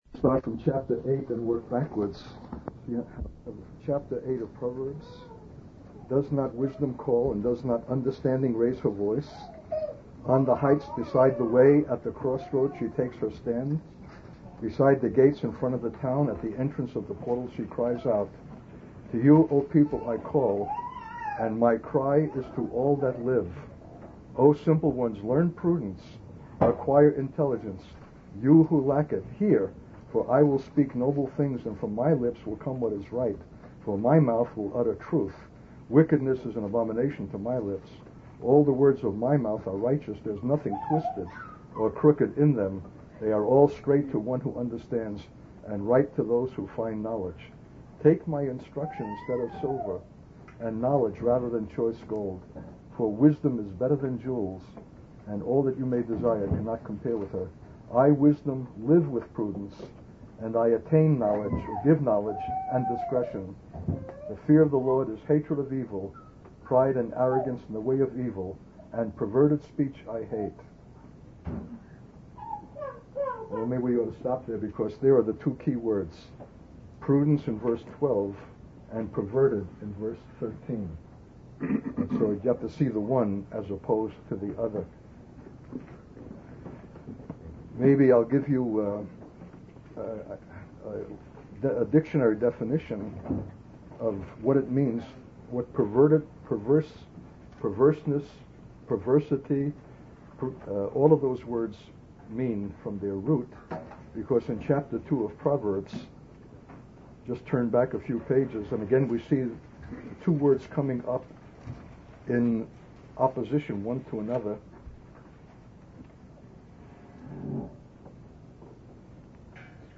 In this sermon, the speaker emphasizes the importance of developing the skill of wisdom and prudence.